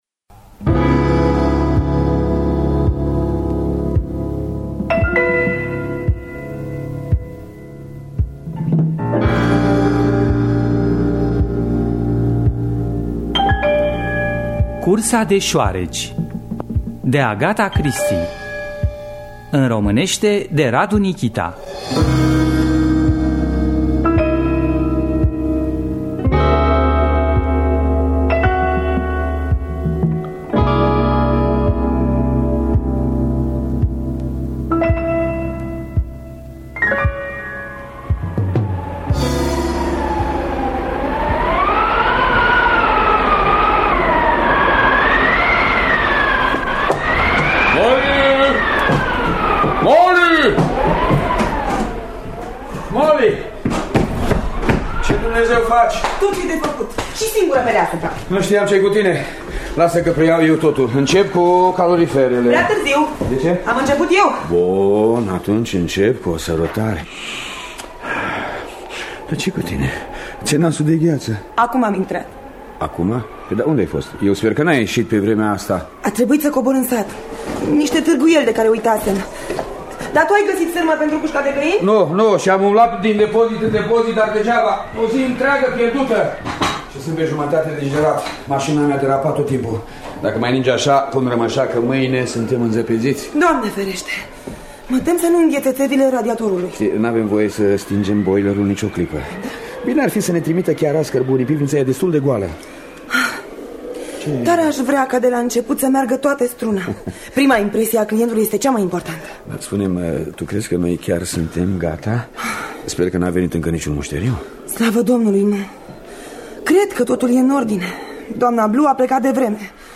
“Cursa de șoareci” de Agatha Christie – Teatru Radiofonic Online